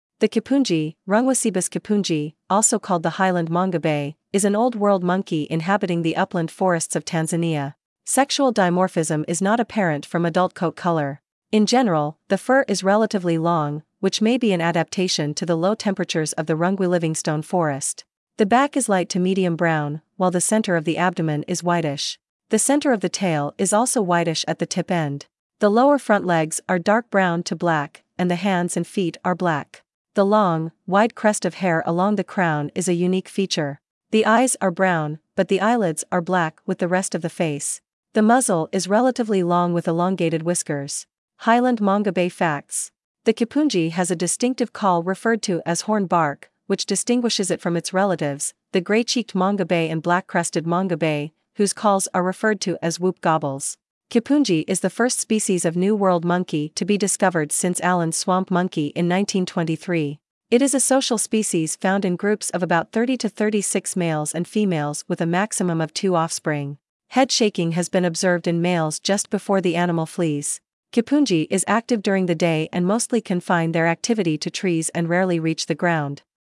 Highland Mangabey
• The kipunji has a distinctive call referred to as “horn bark“, which distinguishes it from its relatives, the Gray-cheeked mangabey and Black-crested mangabey, whose calls are referred to as “whoop-gobbles“.
Highland-mangabey.mp3